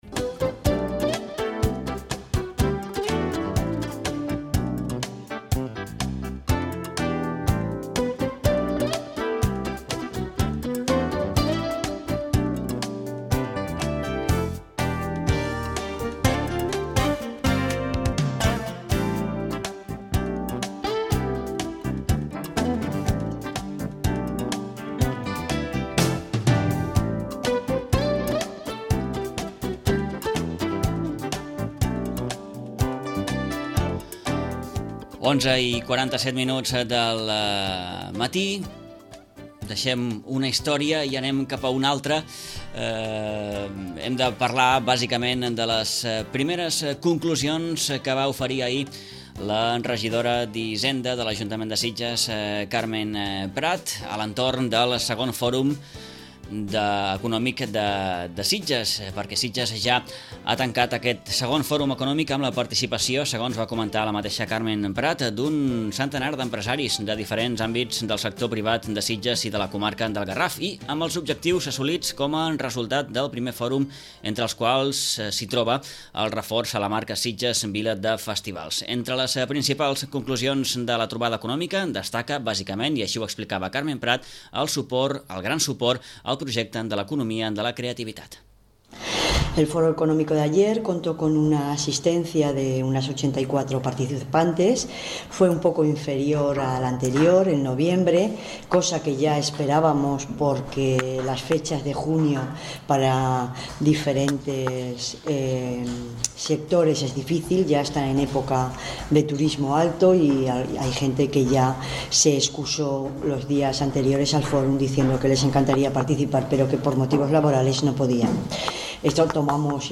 La regidora de l’àrea de Promoció Econòmica, Carmen Prat, ofereix en roda de premsa les primeres conclusions del 2on Fòrum Econòmic de Sitges, que es va celebrar el passat dilluns a l’Hotel Estela i que enguany ha girat a l’entorn de l’economia de la creativitat.